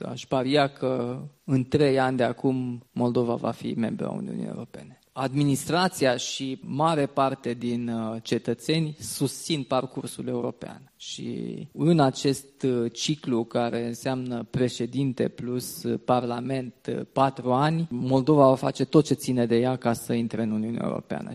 După-amiaza, participanții s-au mutat la Cinema Timiș pentru a participa la conferința cu tema „Europa,  la răscruce  de drumuri   – Securitate, prosperitate și viitorul nostru comun”
În deschidere, după mesajul video de la Marta Kos – Comisară Europeană pentru extinderea Uniunii Europene,  primarul Timișoarei , Dominic  Fritz, a dialogat cu președintele Nicușor Dan.